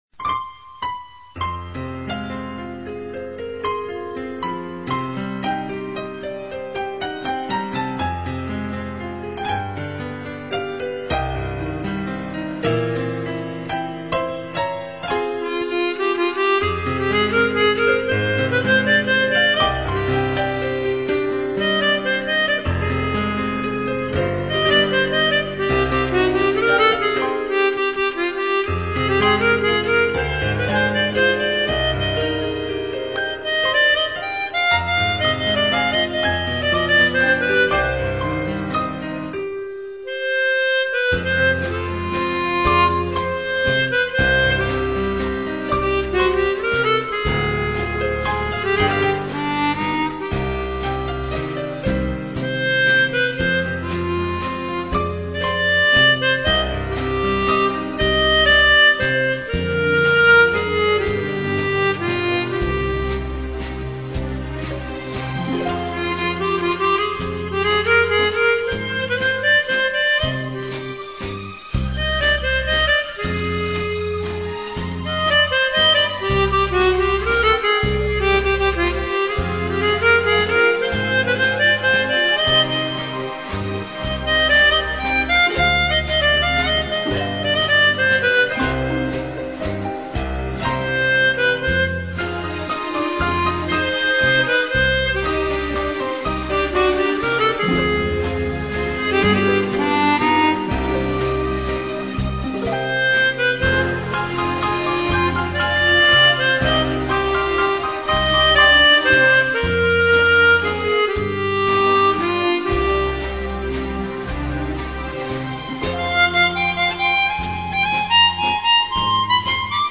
Tonalité de la chanson : Do mineur.
Très beau son.